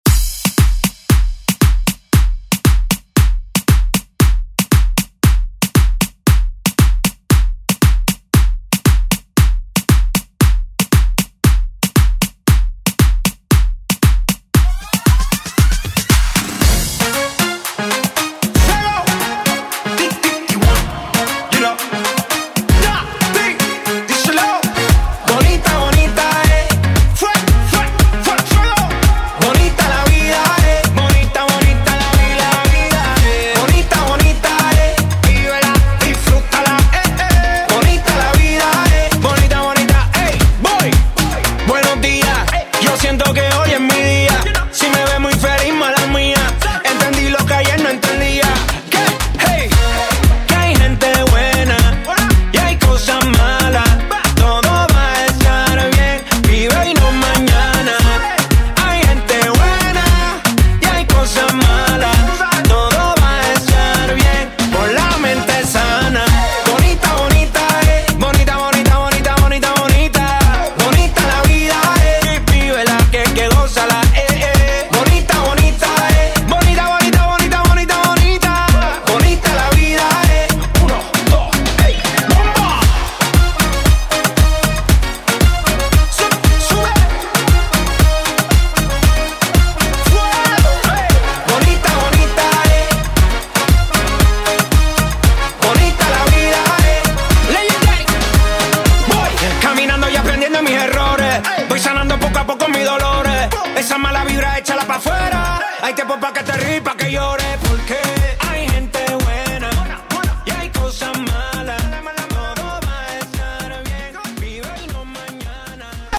Pop Rock
110 bpm
Genre: 80's